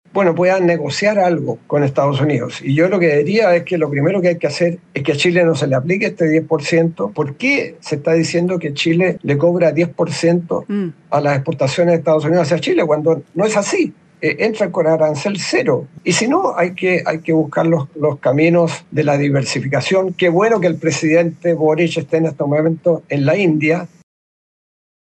En este sentido, el exministro de Relaciones Exteriores, Heraldo Muñoz, en conversación con Expreso Bío Bío, dijo que Chile deberá usar los canales diplomáticos contemplados en el TLC para apelar al arancel del 10% impuesto a nuestro país.